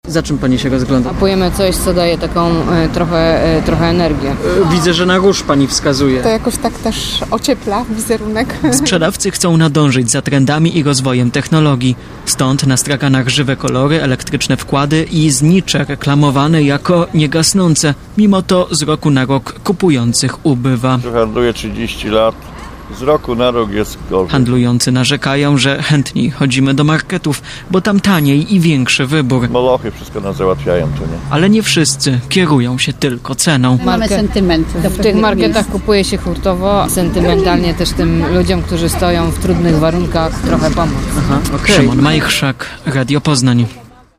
- mówiła jedna z kupujących, wskazując na różowy bukiet.